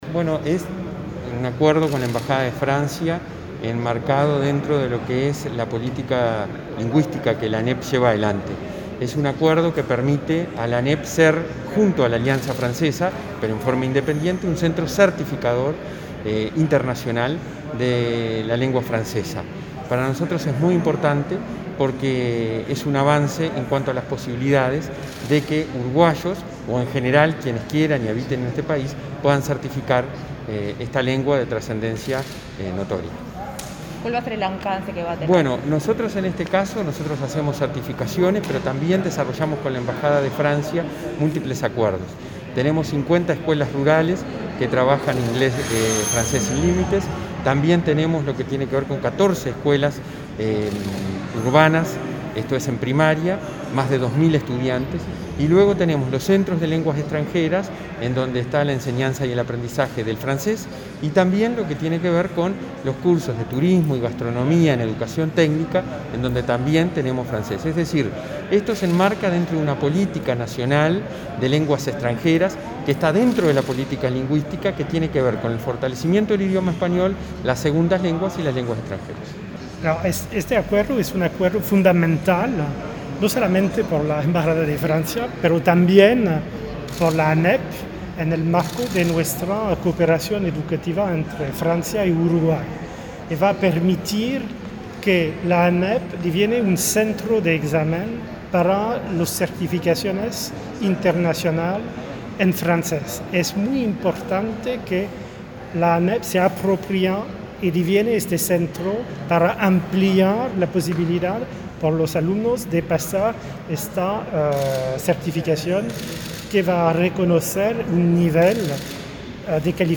La ANEP firmó un convenio con la Embajada de Francia, por el cual recibe el estatus de centro aplicador de los exámenes internacionales DELF, que acreditan el nivel de conocimiento de francés. El presidente de la Administración Nacional de Educación Pública (ANEP), Robert Silva, y el embajador de Francia, Hugues Moret, en declaraciones a Comunicación Presidencial, destacaron la importancia del acuerdo.